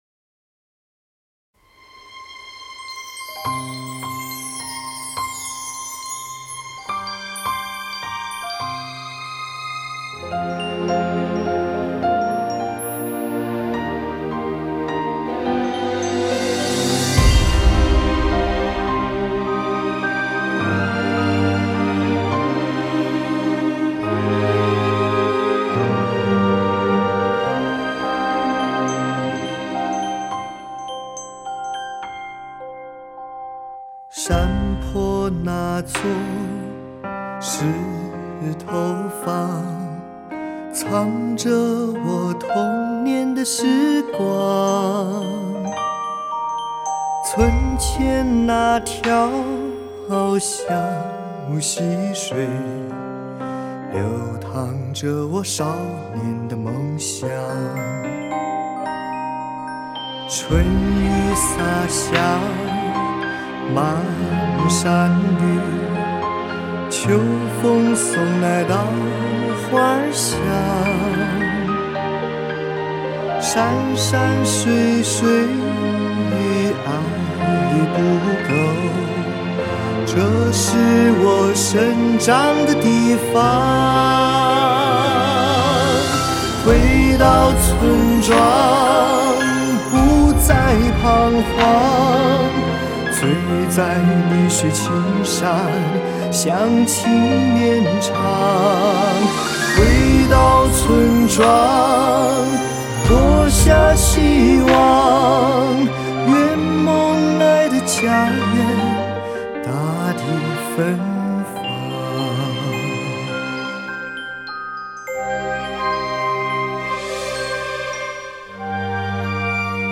新鲜别致，有味耐听。